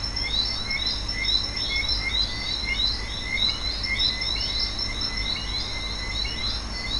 night2.wav